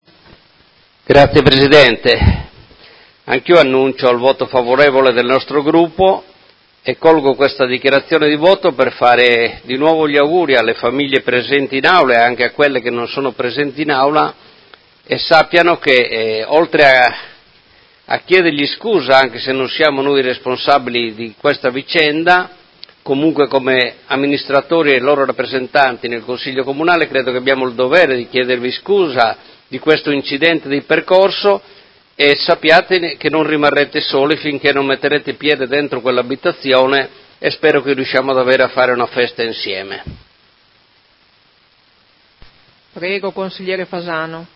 Seduta del 17/01/2019 Dichiarazioni di voto.